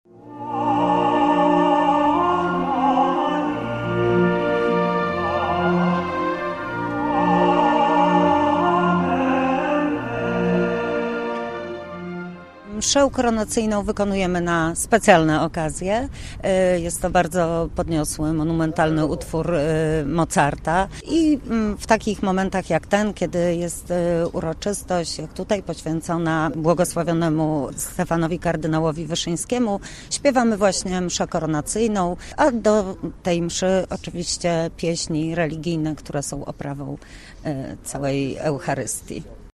Zespół Mazowsze wystąpił podczas mszy świętej w gorzowskiej katedrze.